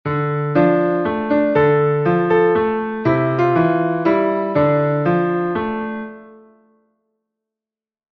Wir können auch die Rhythmik verändern und die Akkorde sowie die Melodie gleich lassen – das Stück ist nach wie vor erkennbar:
Scarborough Fare Akkorde mit alternativem Rhythmus